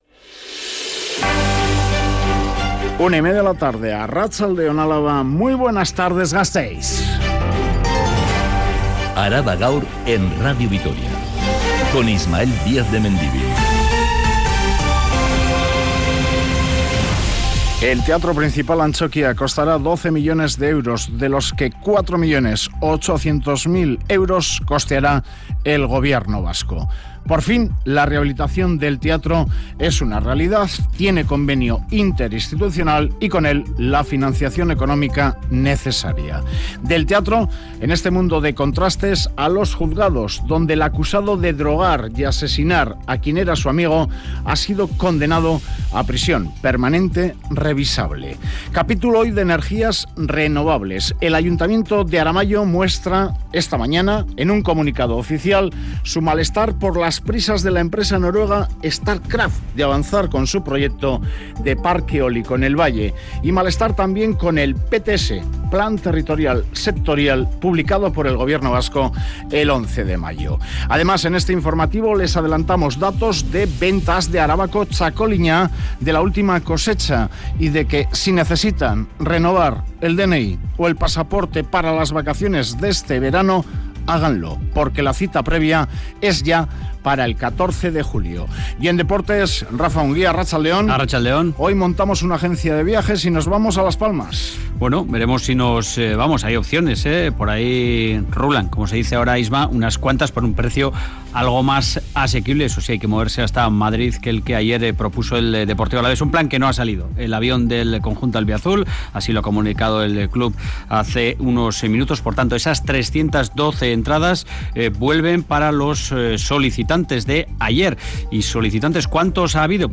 Radio Vitoria ARABA_GAUR_13H Araba Gaur (Mediodía) (23/05/2023) Publicado: 23/05/2023 14:54 (UTC+2) Última actualización: 23/05/2023 14:54 (UTC+2) Toda la información de Álava y del mundo. Este informativo que dedica especial atención a los temas más candentes de la actualidad en el territorio de Álava, detalla todos los acontecimientos que han sido noticia a lo largo de la mañana.